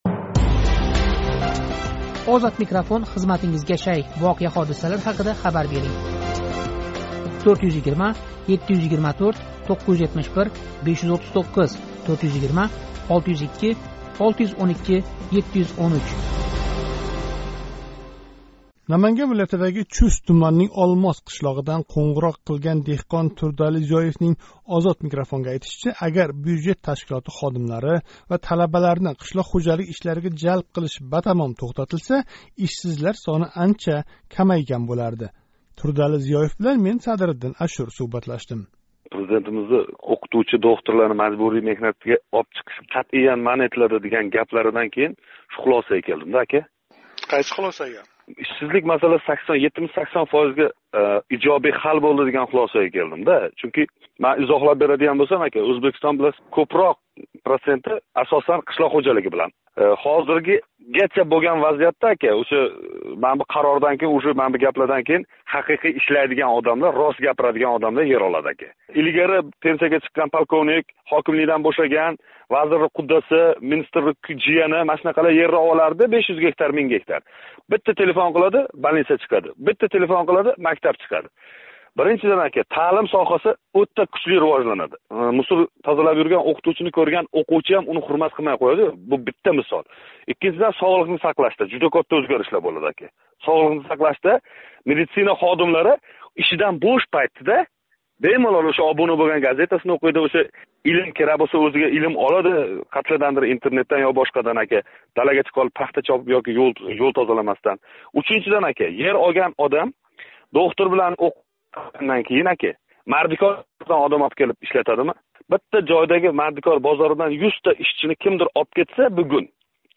қўнғироқ қилган деҳқон